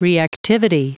Prononciation du mot reactivity en anglais (fichier audio)
reactivity.wav